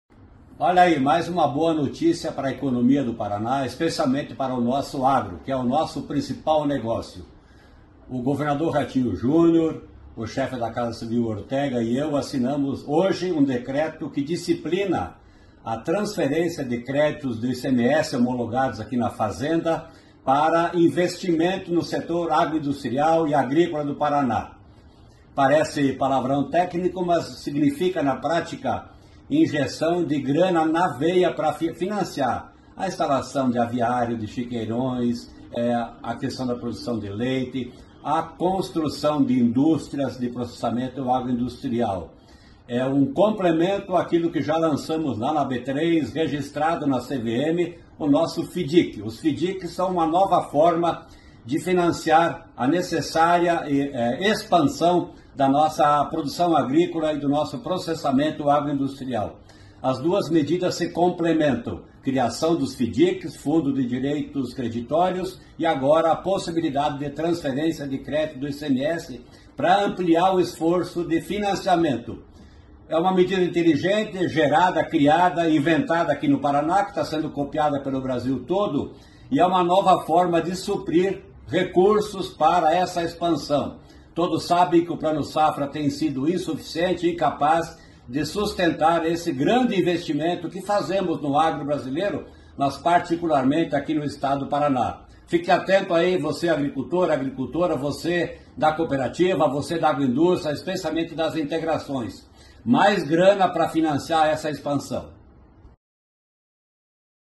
Sonora do secretário Estadual da Fazenda, Norberto Ortigara, sobre a definição de regras para o Paraná FIDC